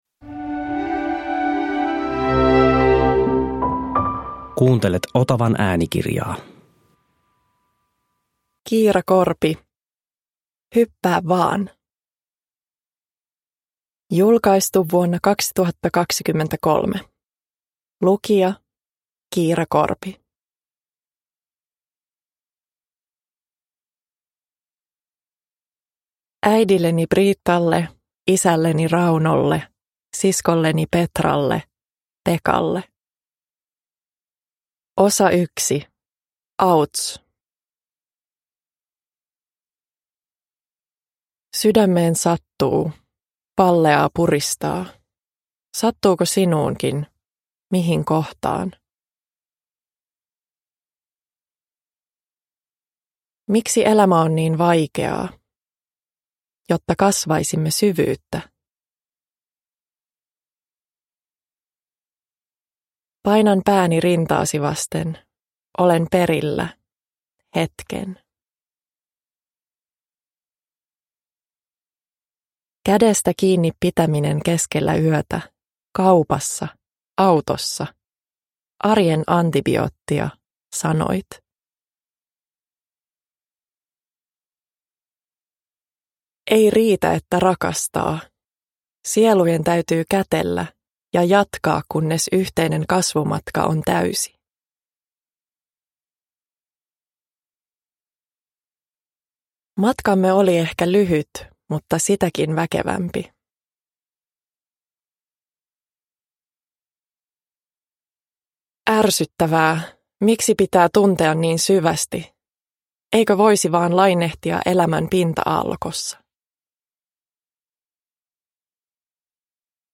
Hyppää vaan! – Ljudbok
Uppläsare: Kiira Korpi